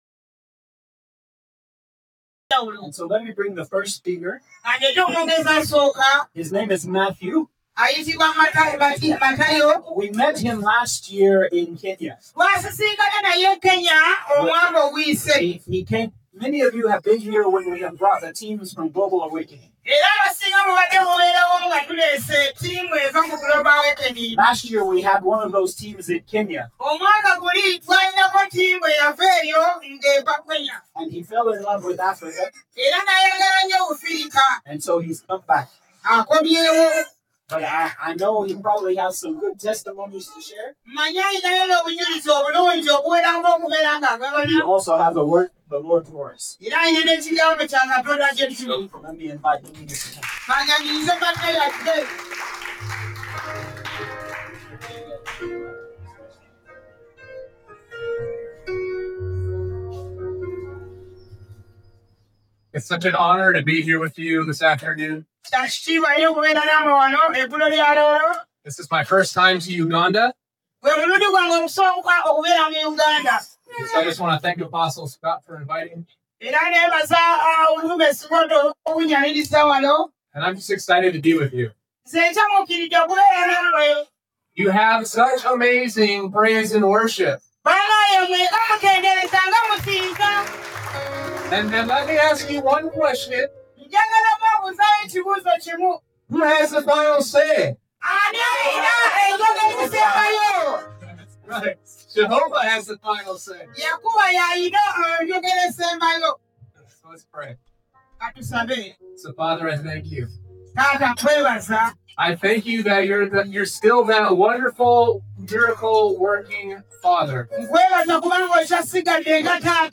Sermons | Sozo Ministries